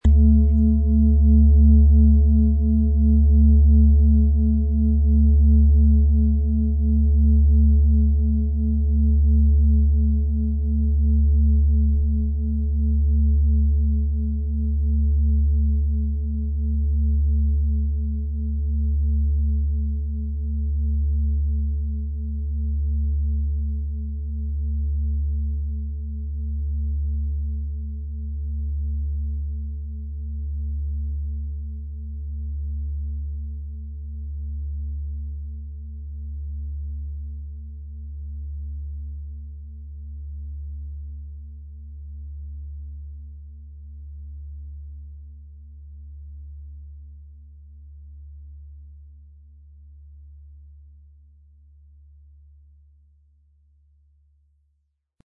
Planetenton 1 Planetenton 2
So entsteht ein lebendiger, charakterstarker Klang.
• Tiefster Ton: Mars